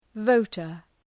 Προφορά
{‘vəʋtər}